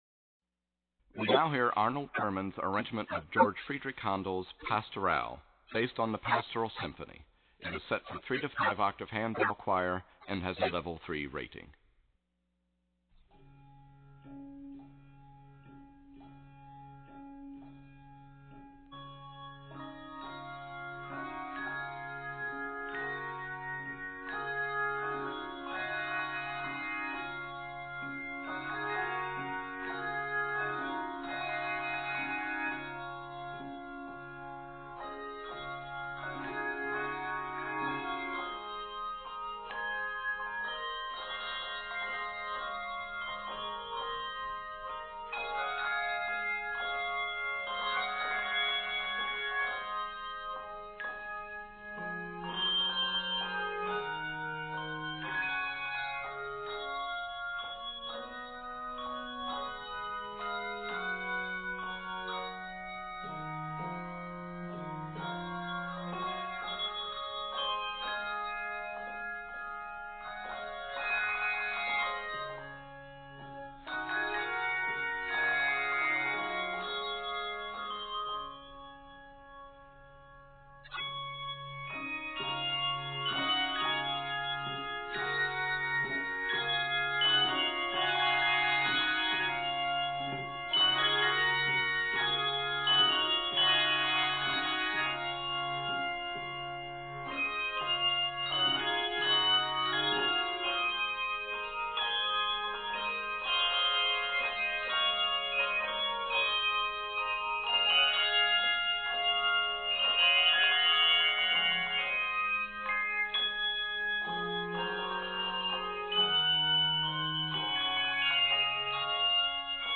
This piece is a great workout on dotted rhythms.
Octaves: 3-5